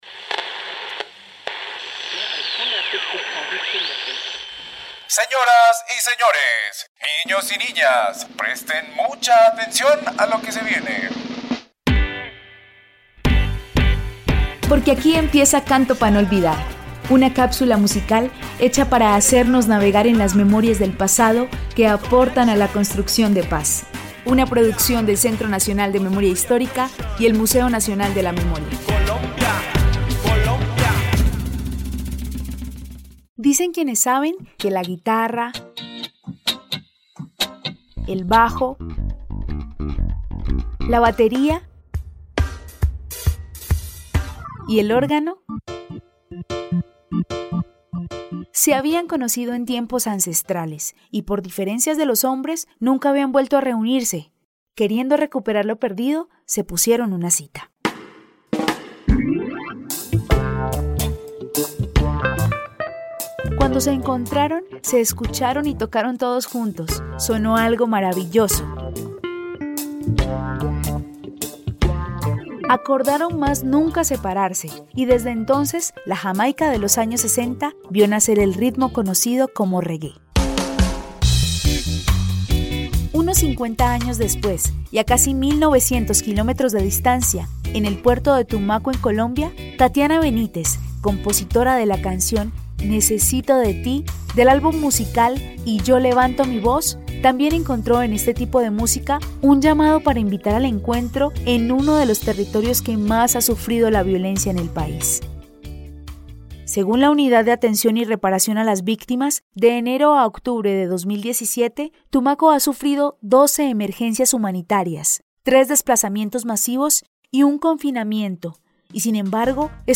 Canción